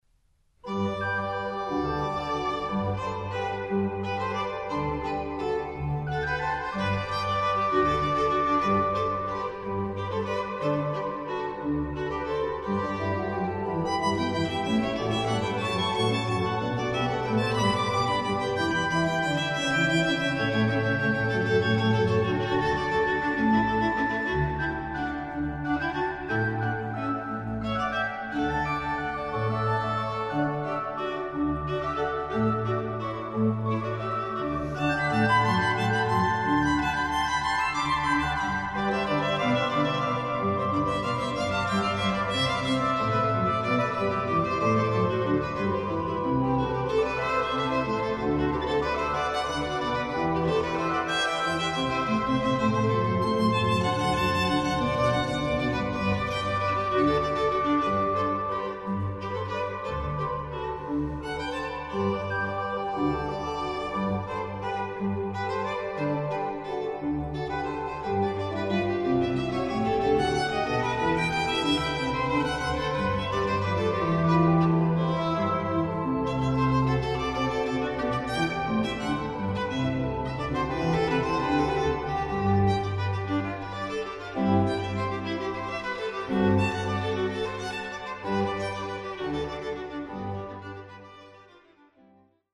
Beginn des 1. Satzes auf historischen Instrumenten